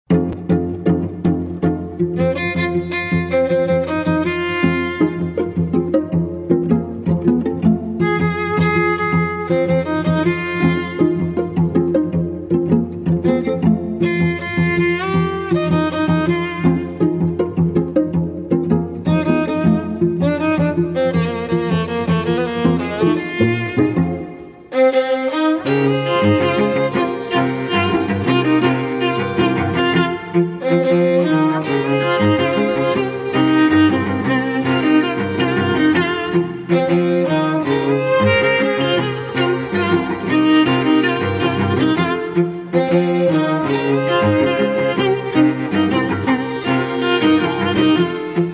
Strings 2